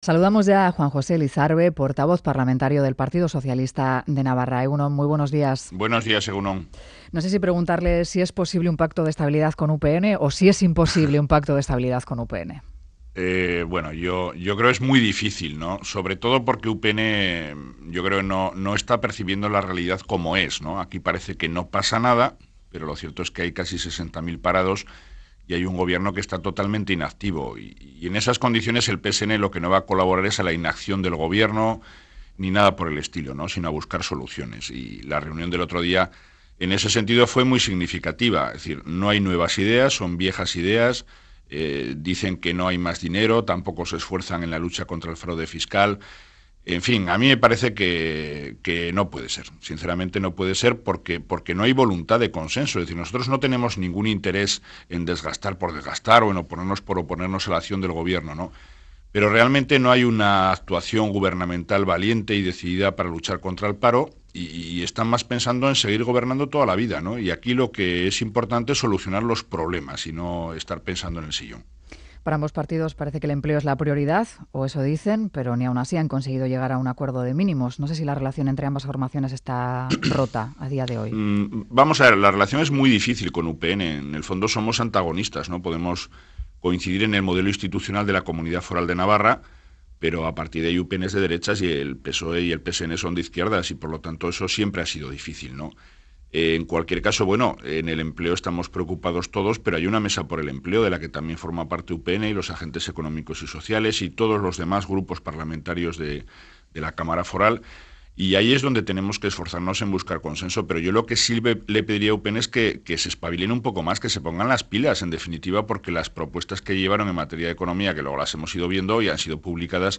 Entrevista a Juan José Lizarbe, portavoz parlamentario de PSN